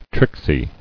[trick·sy]